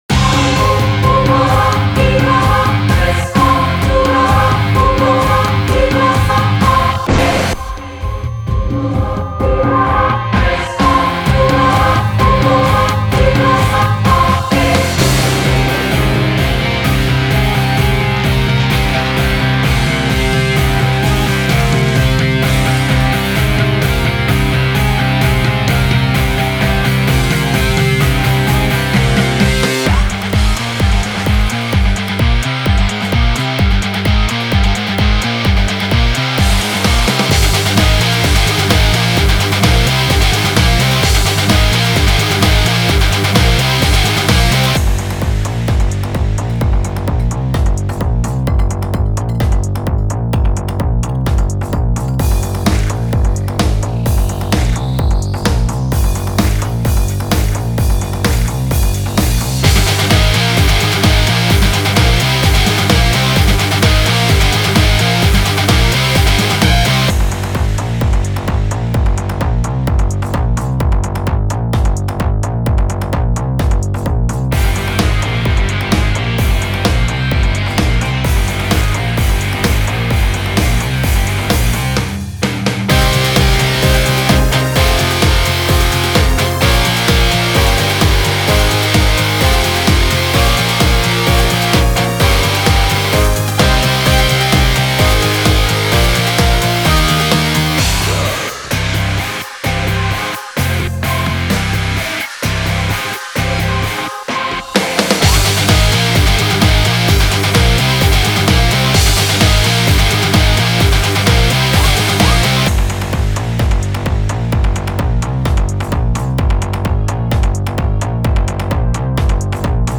Здесь верх пока жестко песочит, но в целом как идея это такой раммшайно-образный трек, мне кажется неплохо))